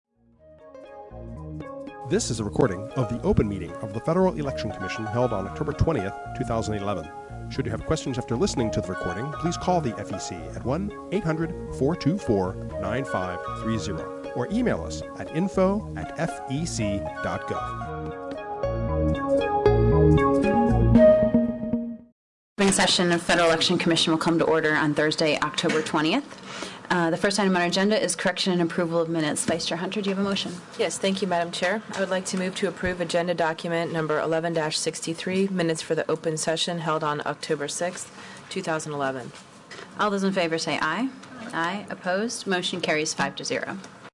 October 20 2011 open meeting